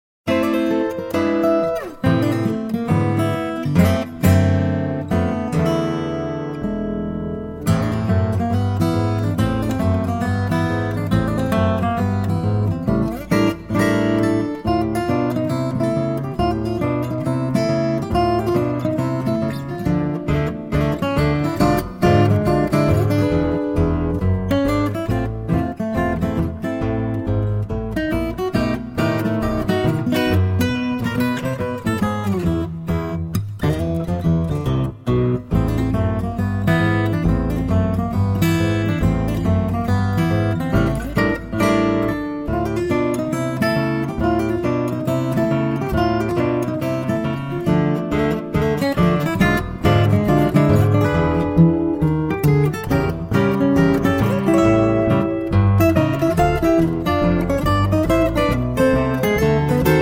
fingerstyle instrumentals
Guitar